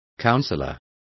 Complete with pronunciation of the translation of counselors.